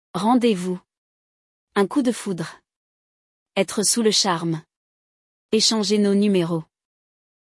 Neste episódio, você vai escutar um diálogo entre duas pessoas que se conheceram em um speed dating e aproveitar para aprender como usar a preposição DE nos contextos de localização e partida.